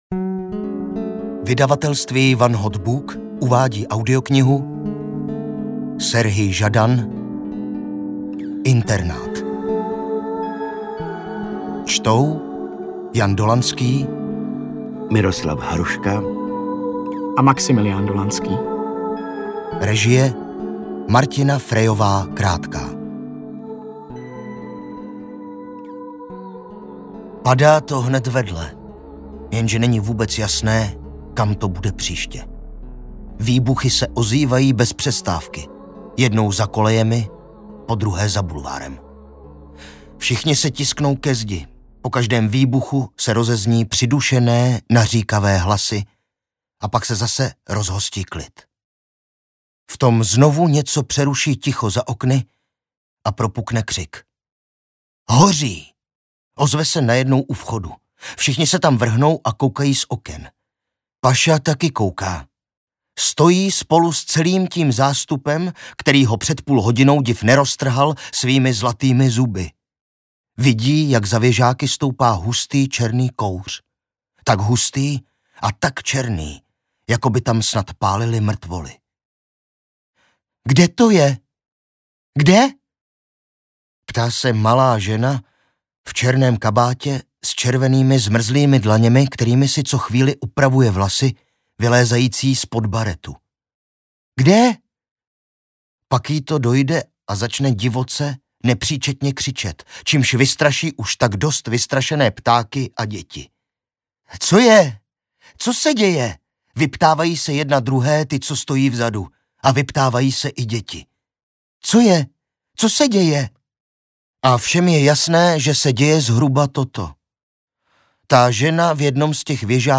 Internát audiokniha
Ukázka z knihy